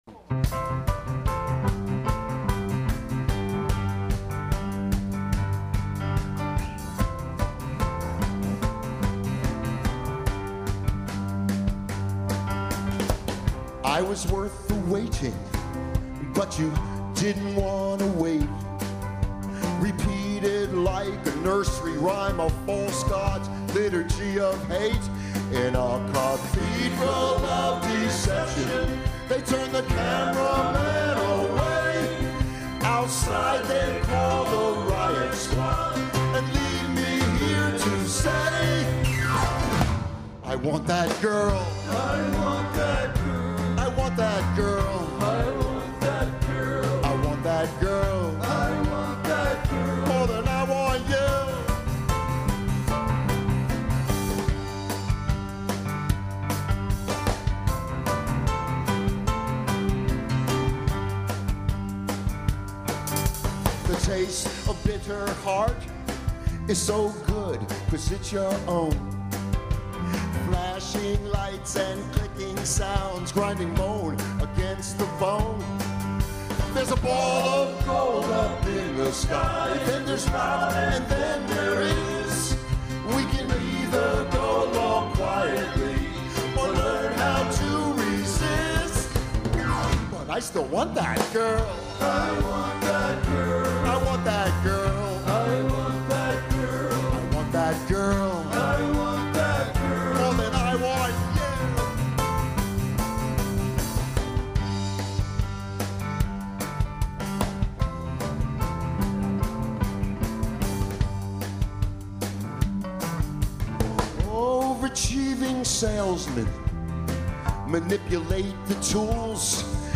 The last gig
cbgb 2006